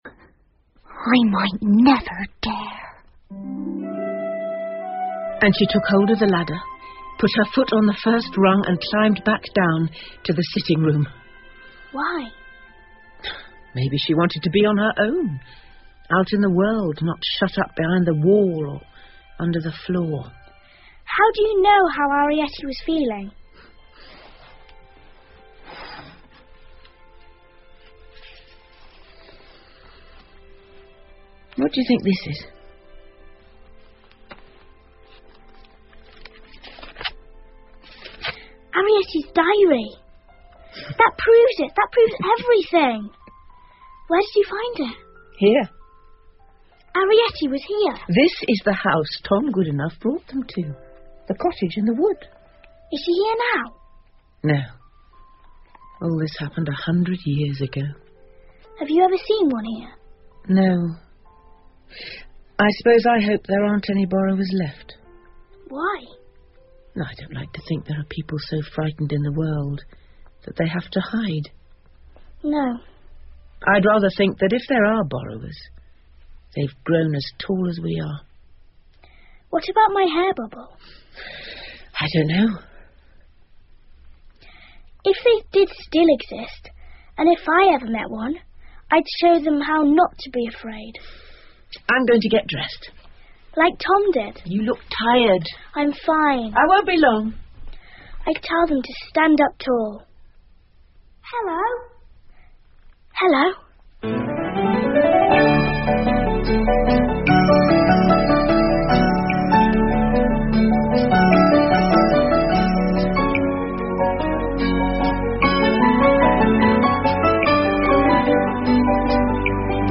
借东西的小人 The Borrowers 儿童广播剧 18 听力文件下载—在线英语听力室